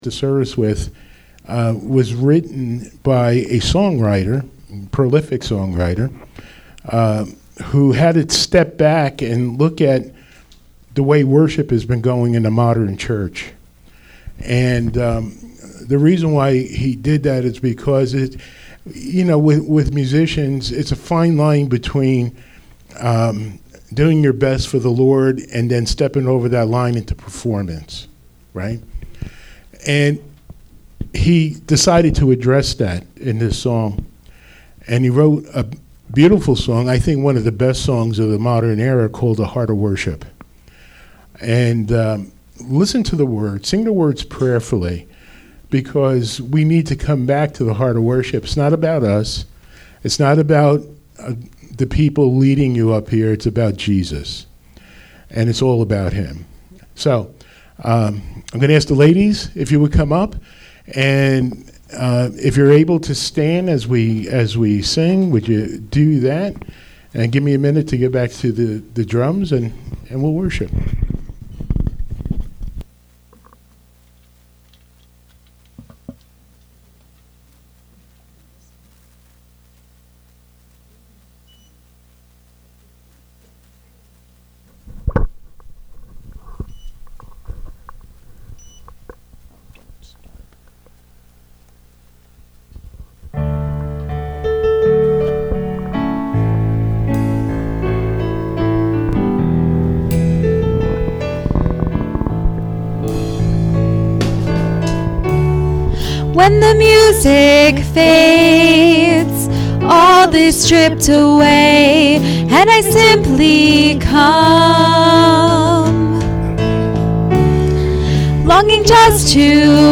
On this pastor appreciation day. you will be blessed by this service and message.